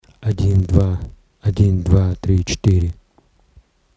Цифровой шум на преобразованном сигнале (audiophile 2496)
После записи в карту на аудио сигнале появляются характерные трески. После переустановки драйвера проблема была решена ровно на 15 минут!